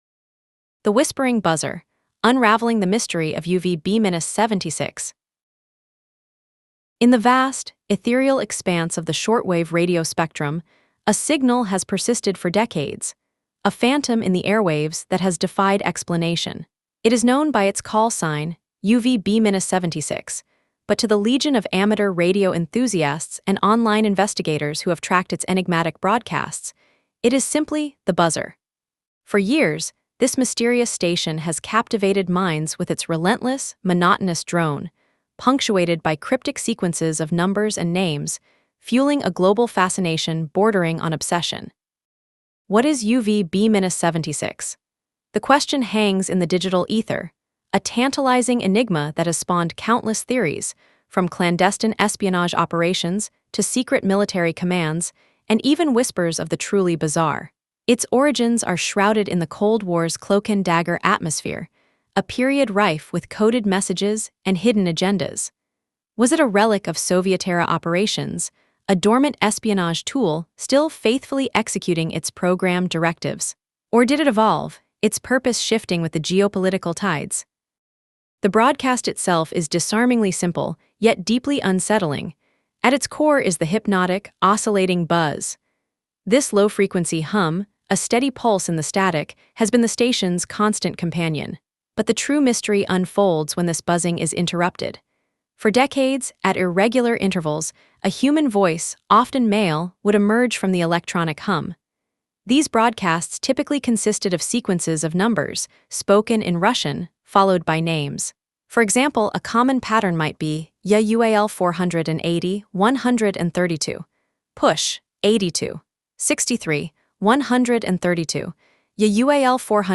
For years, this mysterious station has captivated minds with its relentless, monotonous drone, punctuated by cryptic sequences of numbers and names, fueling a global fascination bordering on obsession.
At its core is the hypnotic, oscillating “buzz.” This low-frequency hum, a steady pulse in the static, has been the station’s constant companion.
For decades, at irregular intervals, a human voice, often male, would emerge from the electronic hum.